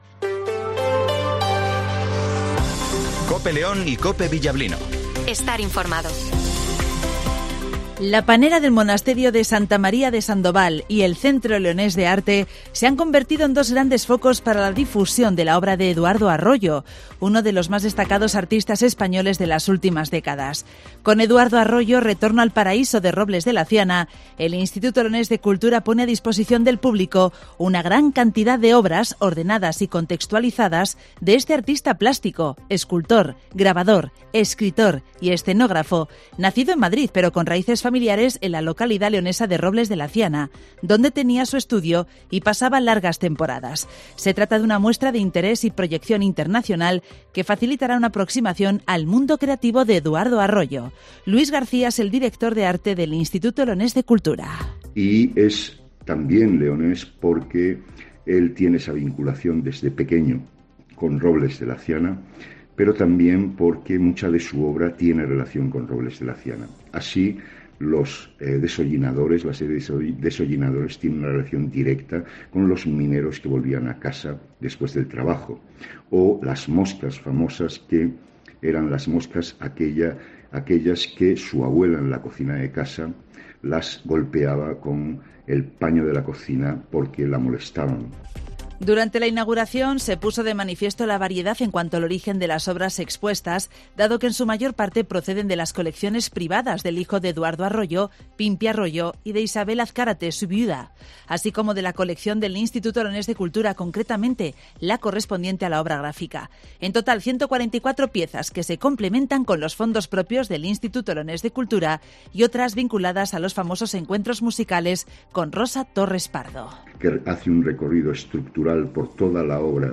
Redacción digital Madrid - Publicado el 12 jun 2023, 08:20 - Actualizado 13 jun 2023, 16:23 1 min lectura Descargar Facebook Twitter Whatsapp Telegram Enviar por email Copiar enlace - Informativo Matinal 08:20 h